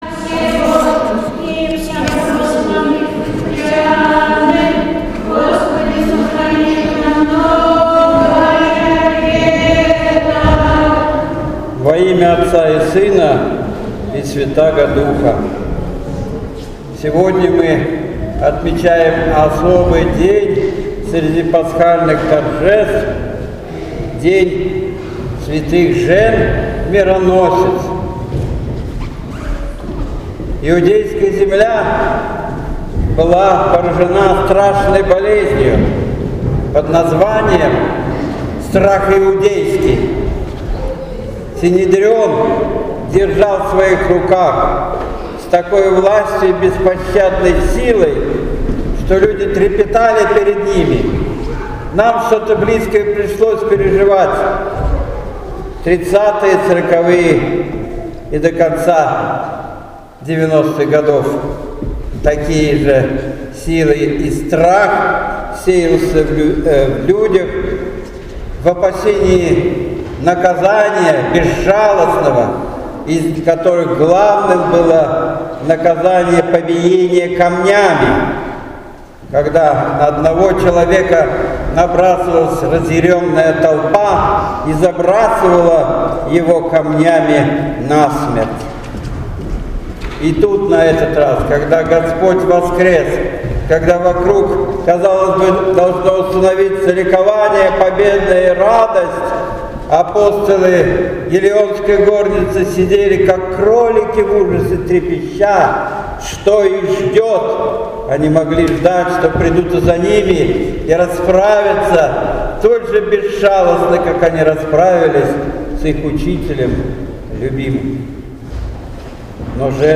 Божественная Литургия 30 апреля 2017 года.Неделя святых жен-мироносиц